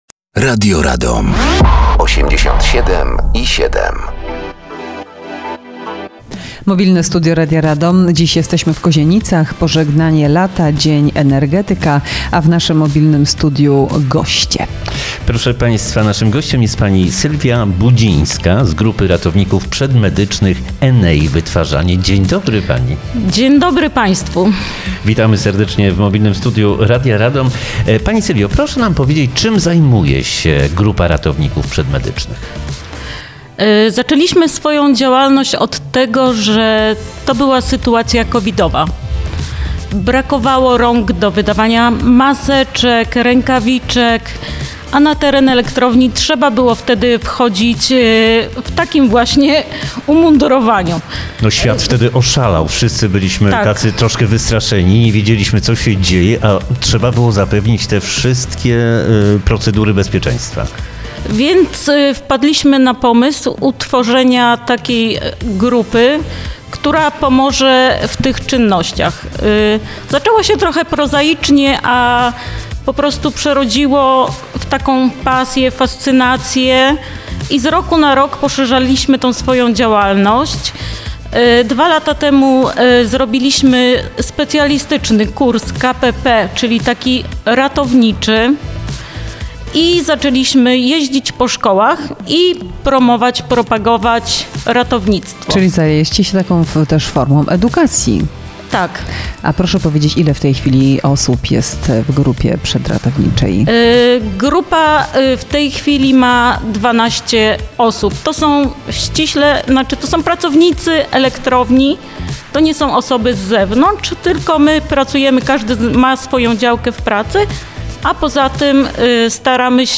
Dzień Energetyka Pożegnanie Lata 2025.